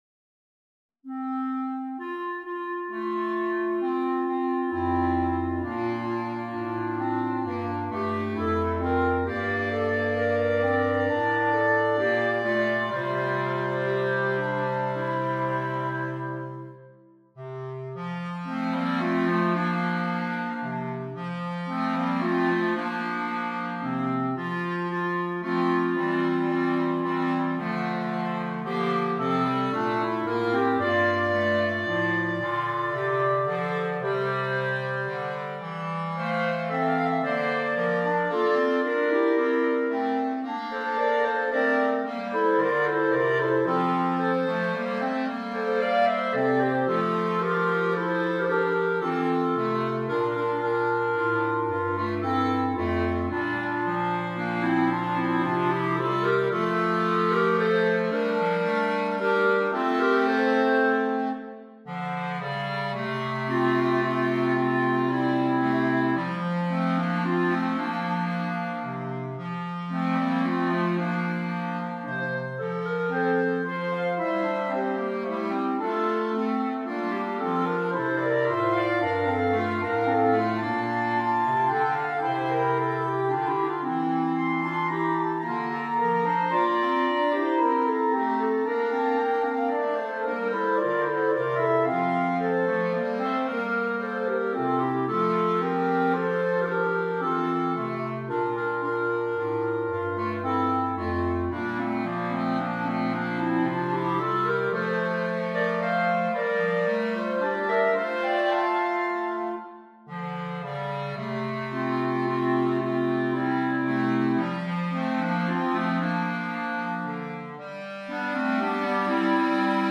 A lovely lilting arrangement of American Folk Song
in 6/8 time for Clarinet Quartet
Folk and World